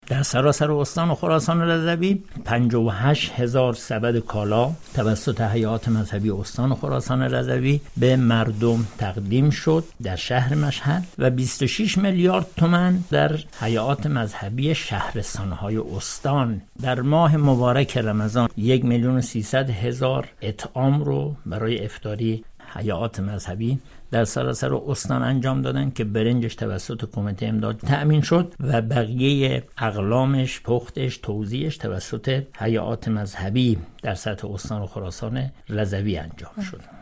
گفتگوی ویژه خبری